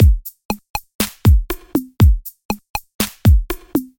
描述：RnB 嘻哈 电子音乐 寒意盎然
Tag: 120 bpm RnB Loops Drum Loops 689.17 KB wav Key : Unknown Mixcraft